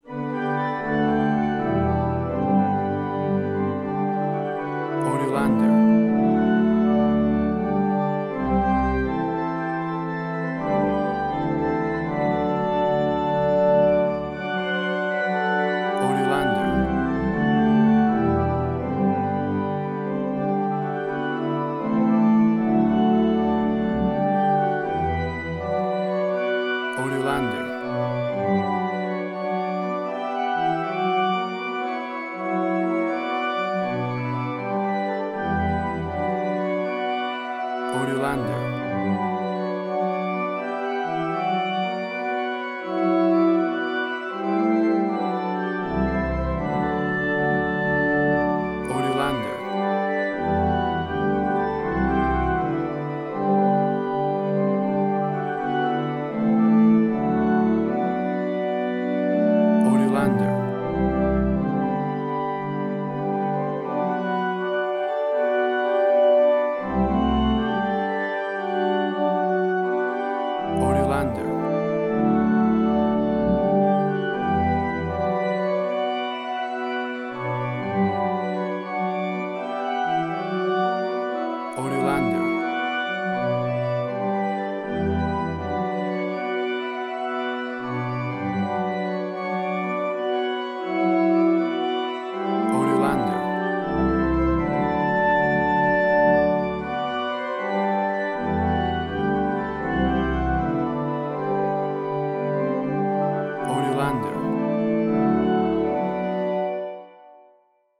A vibrant and heartwarming church organ version
WAV Sample Rate: 16-Bit stereo, 44.1 kHz